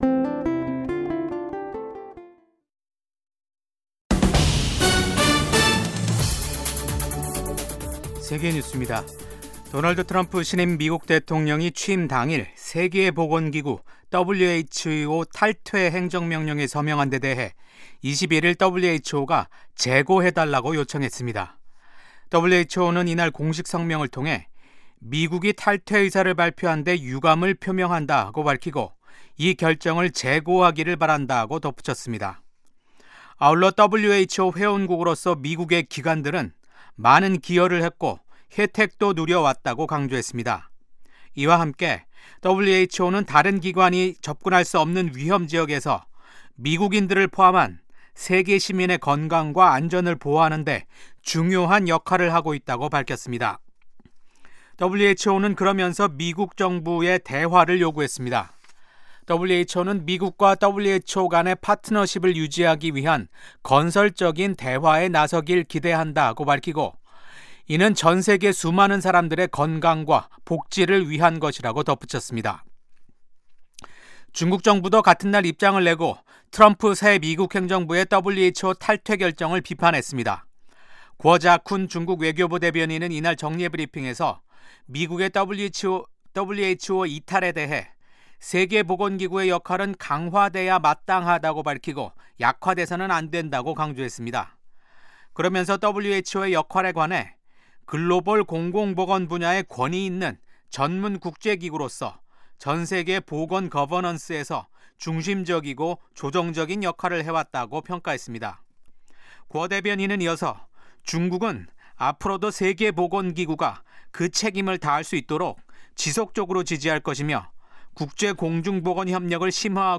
VOA 한국어 아침 뉴스 프로그램 '워싱턴 뉴스 광장'입니다. 도널드 트럼프 미국 대통령이 4년 만에 백악관에 복귀했습니다. 취임 첫날 트럼프 대통령은 북한을 ‘핵보유국’이라고 지칭해 눈길을 끌었습니다.